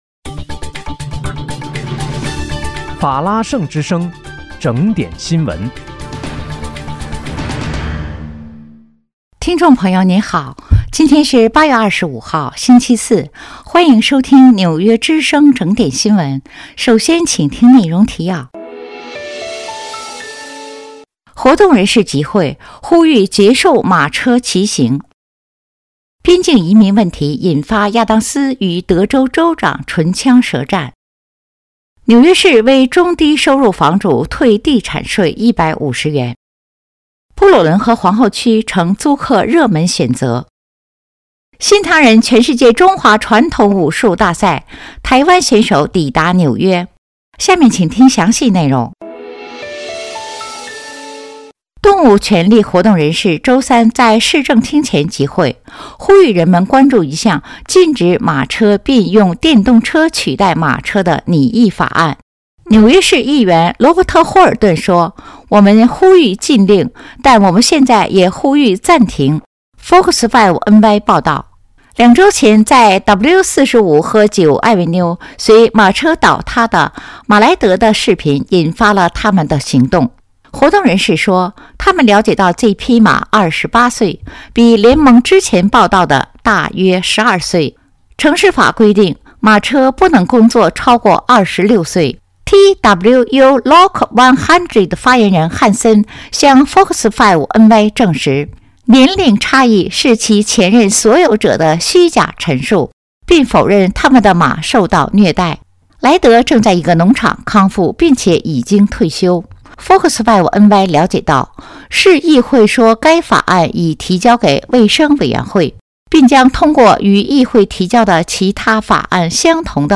8月25日（星期四）纽约整点新闻